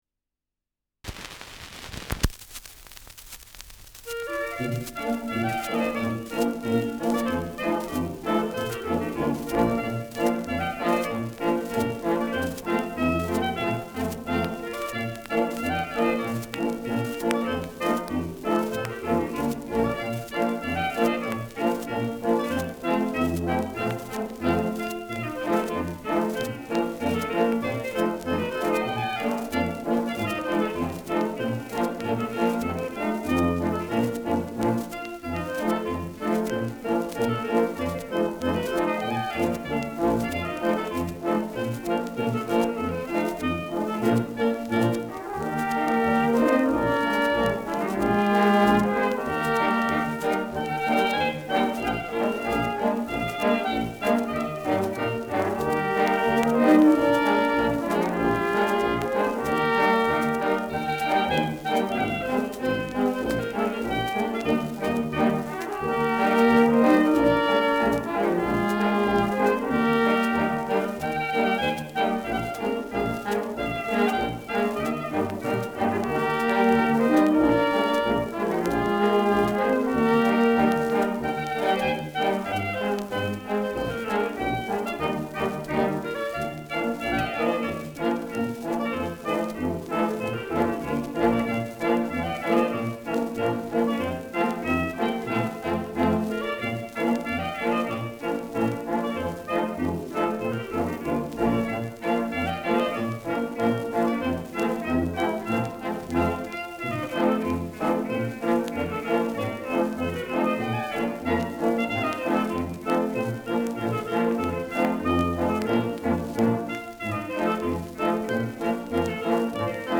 Schellackplatte
Leichtes Grundrauschen : Durchgehend leichtes Knacken
[München] (Aufnahmeort)